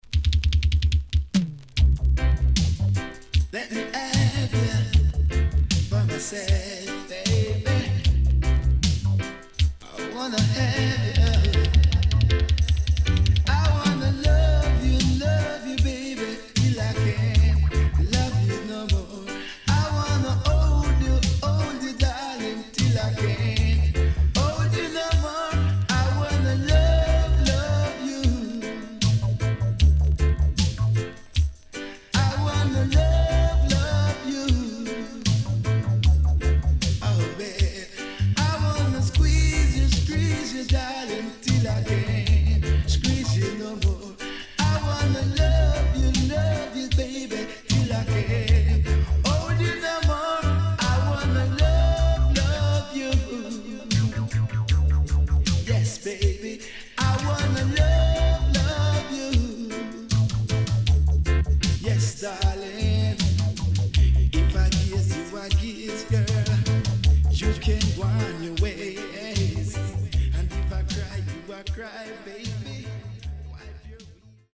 HOME > DISCO45 [DANCEHALL]
SIDE A:少しチリノイズ入ります。